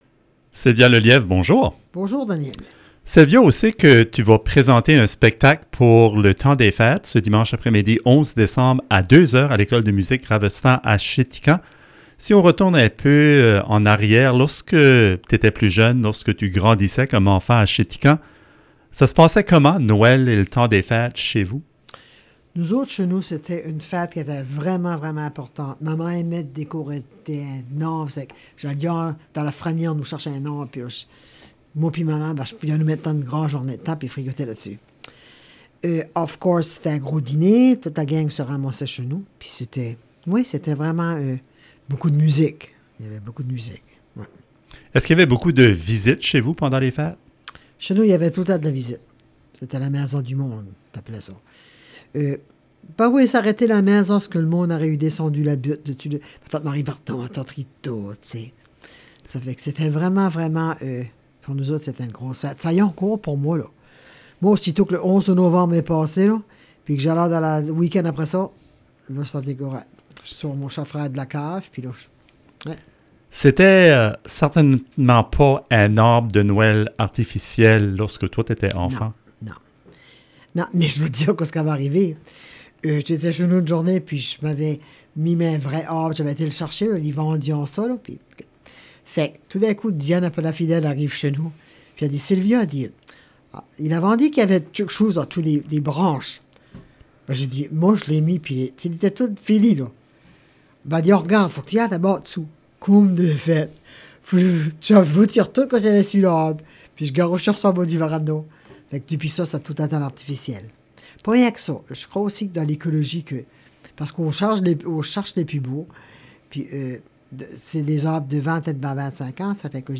Rencontrée dans les studios de Radio CKJM